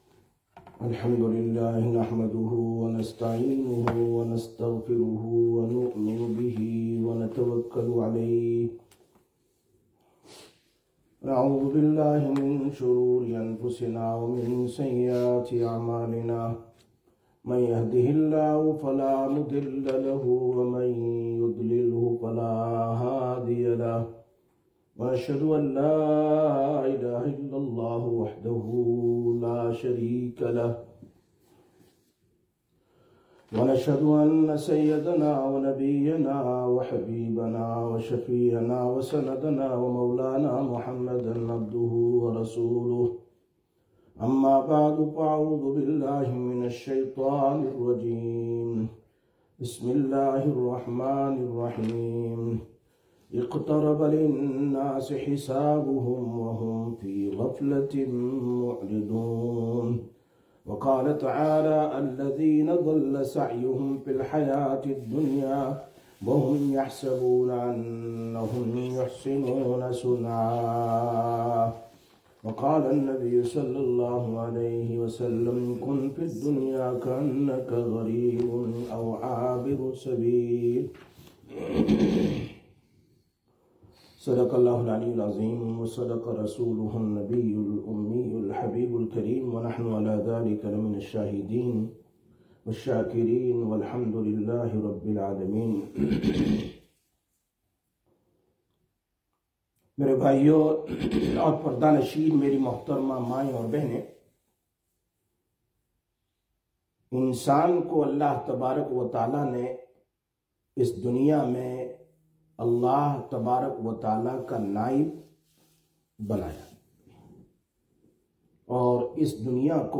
15/01/2024 Sisters Bayan, Masjid Quba